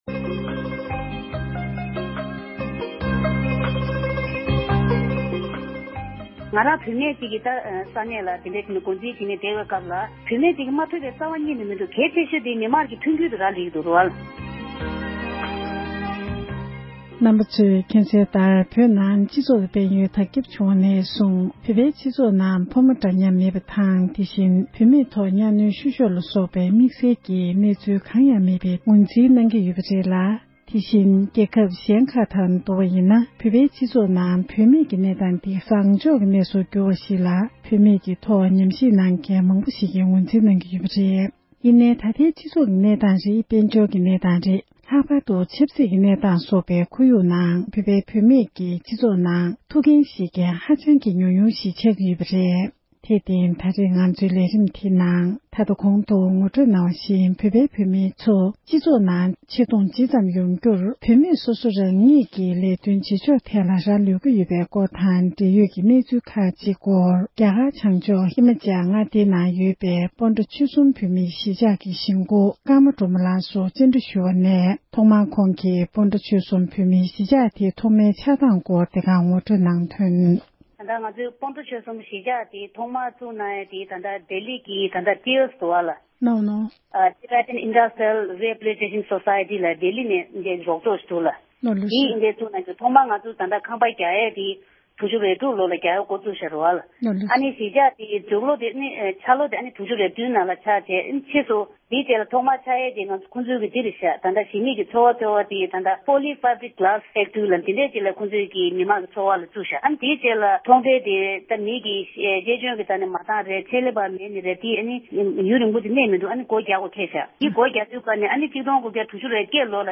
བོད་པའི་སྤྱི་ཚོགས་ནང་ནུས་སྟོབས་ལྡན་པའི་བུད་མེད་ཞིག་ལ་གནས་འདྲི་ཞུས་པར་གསན་རོགས༎